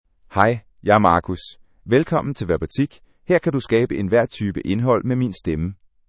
MaleDanish (Denmark)
Marcus — Male Danish AI voice
Marcus is a male AI voice for Danish (Denmark).
Voice sample
Listen to Marcus's male Danish voice.
Marcus delivers clear pronunciation with authentic Denmark Danish intonation, making your content sound professionally produced.